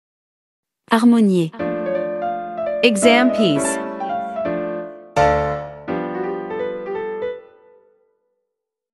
• 人声数拍